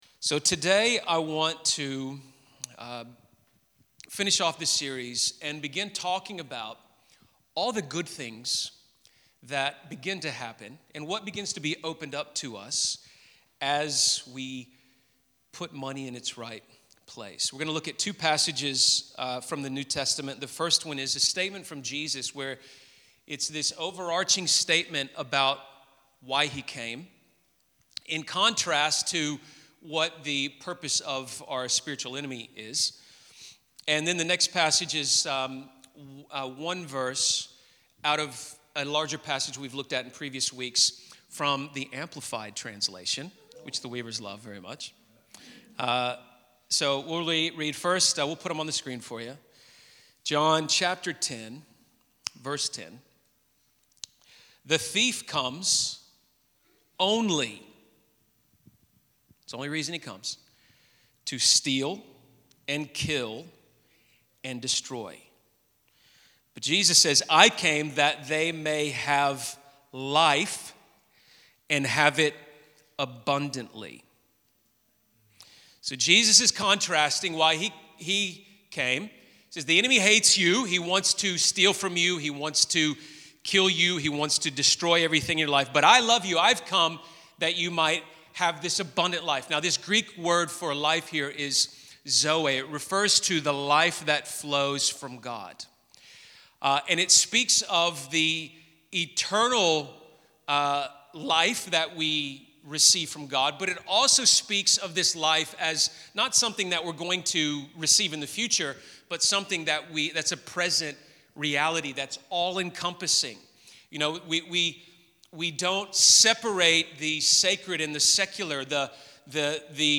When the idol of money has been dethroned, the windows of heaven open, and we can experience the abundant life God has for us. In this message, we look at the theology of abundance, the mindset of abundance, and the practices of abundance. This is the third and final message in the Jesus Over Money series.